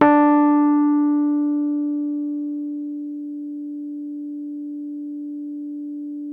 RHODES CL0BL.wav